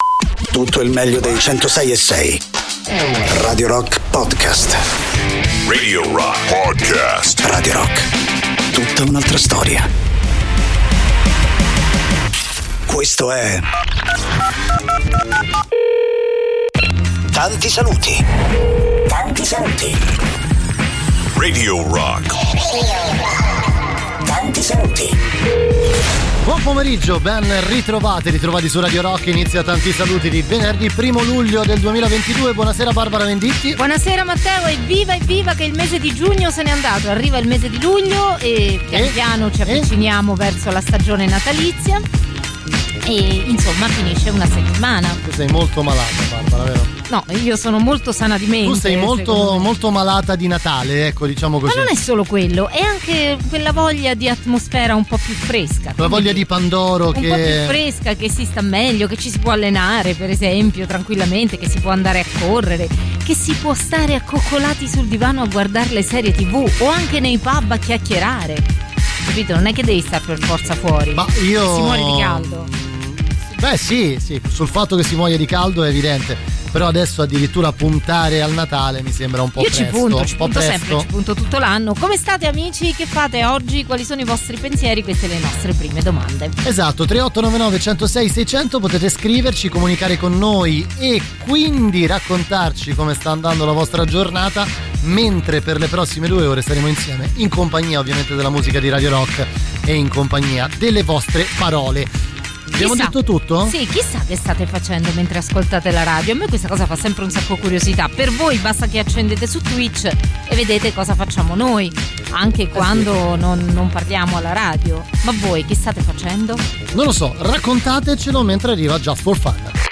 in diretta dal lunedì al venerdì, dalle 19 alle 21, con “Tanti Saluti” sui 106.6 di Radio Rock.